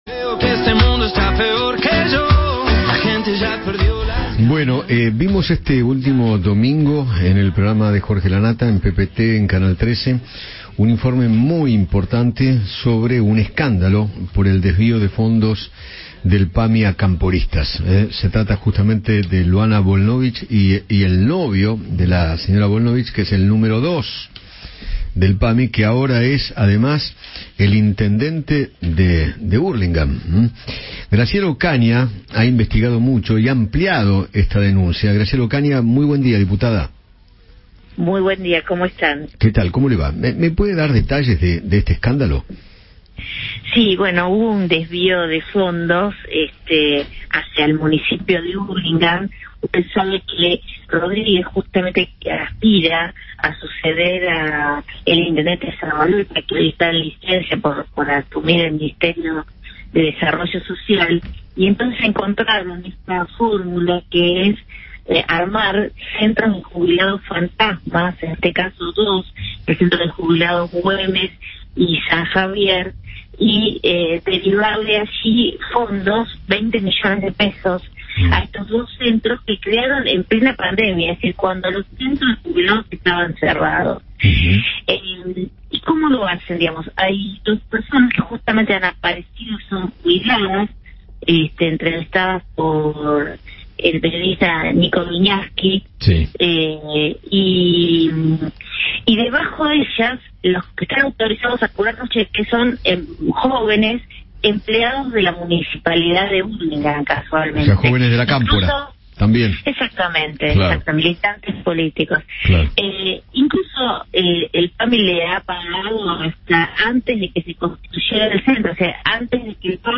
Graciela Ocaña, diputada nacional de Juntos por el Cambio, charló con Eduardo Feinmann sobre los desvíos de fondos del PAMI, luego del informe que presentó Jorge Lanata en PPT.